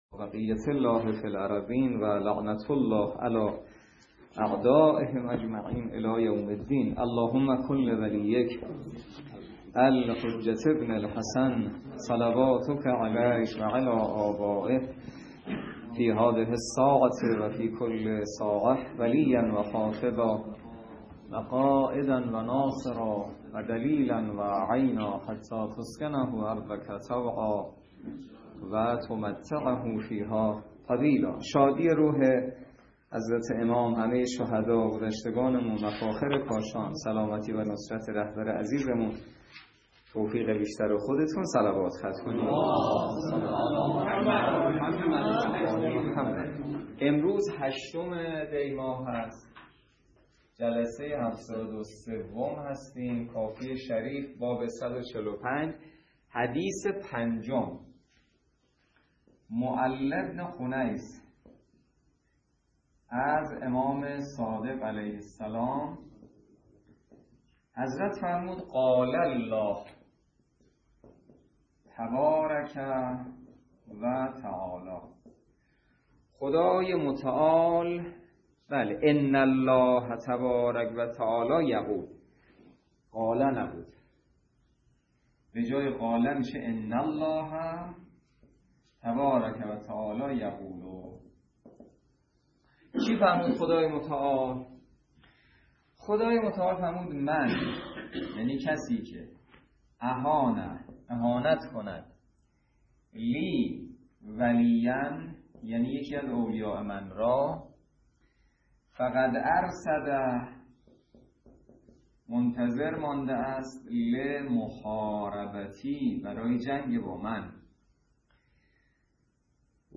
درس فقه الاجاره نماینده مقام معظم رهبری در منطقه و امام جمعه کاشان - سال سوم جلسه هفتاد و سه